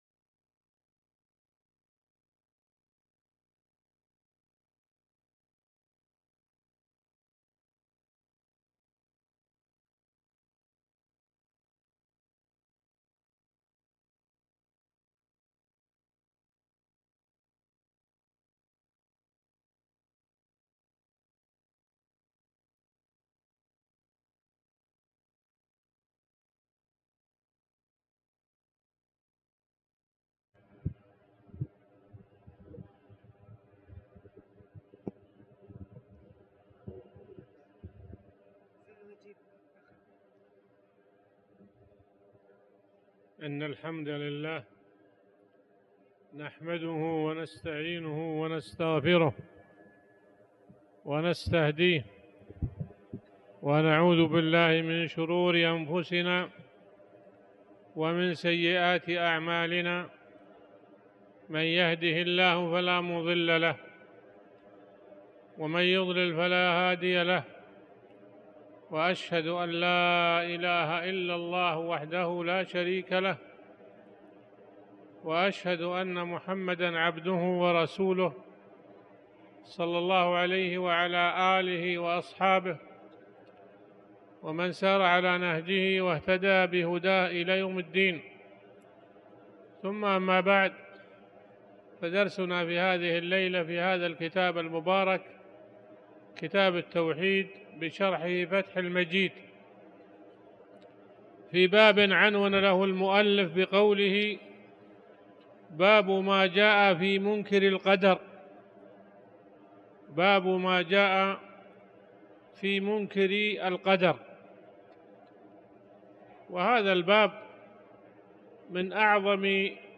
تاريخ النشر ٤ ربيع الثاني ١٤٤٠ هـ المكان: المسجد الحرام الشيخ